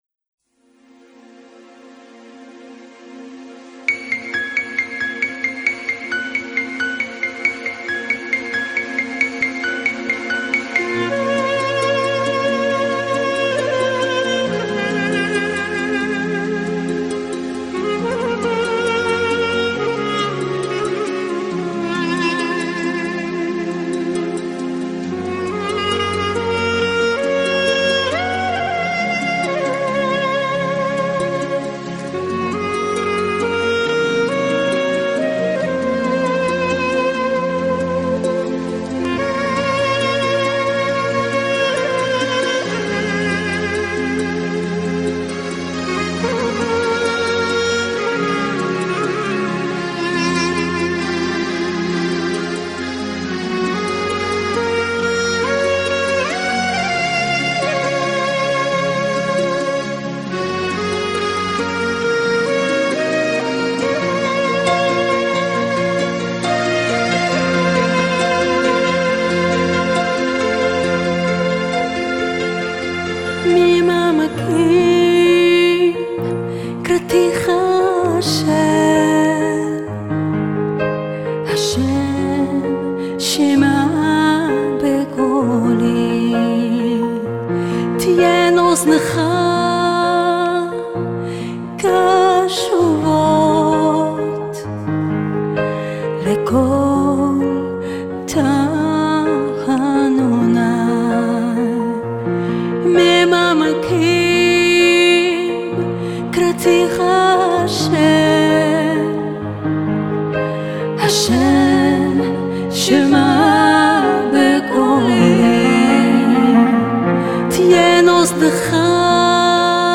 полностью (в песне он лишь частично поется)